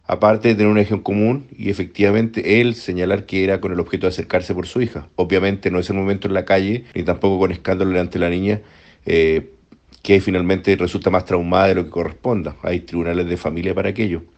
Fiscal-1.mp3